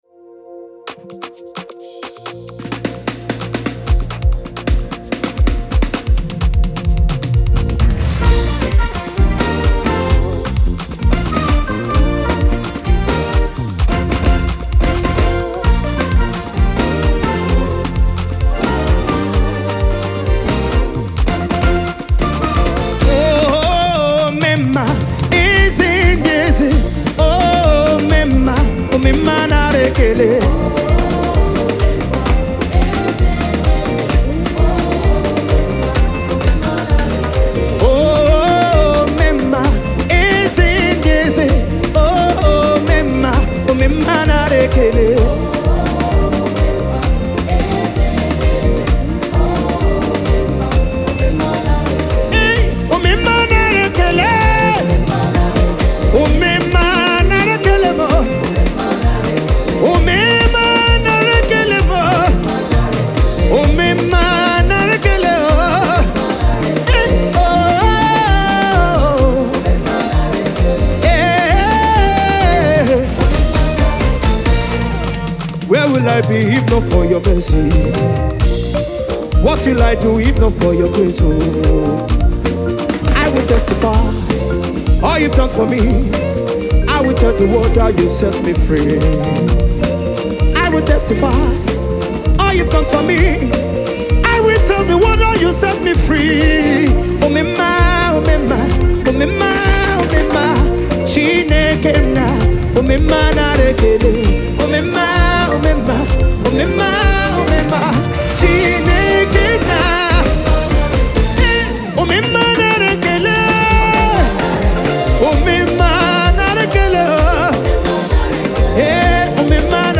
Afro-Gospel